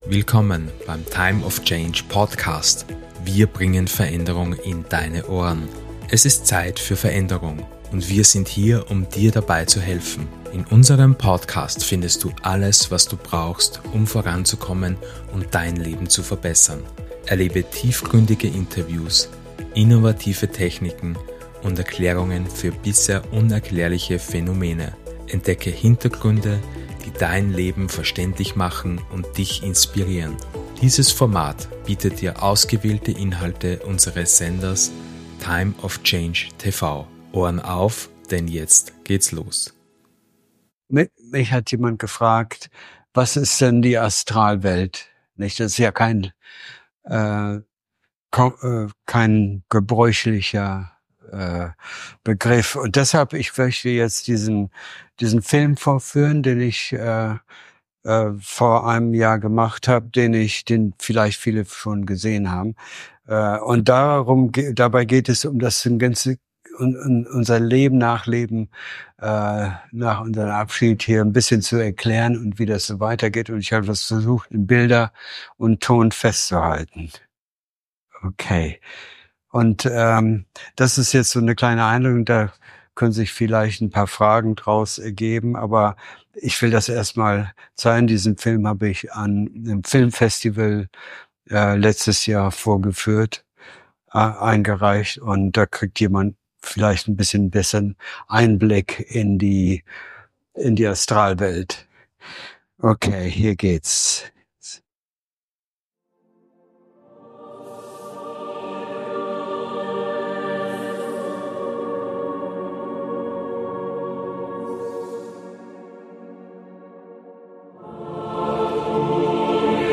Workshop